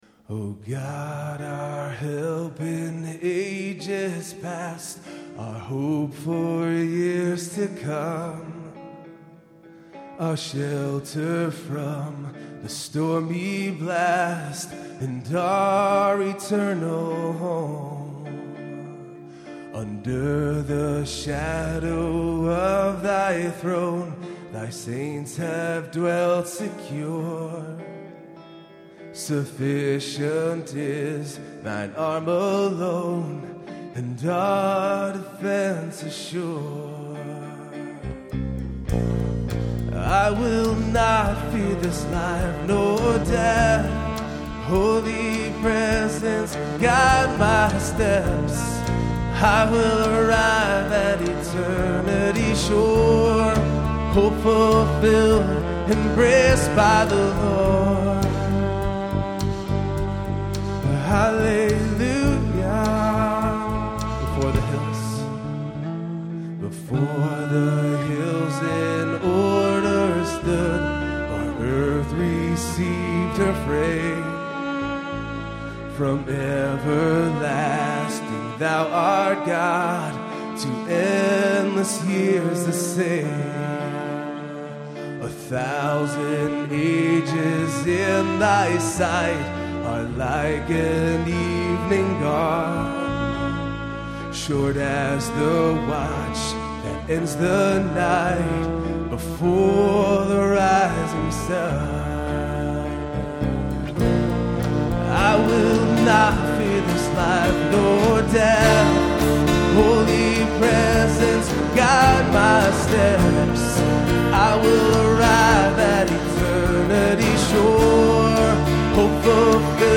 Performed live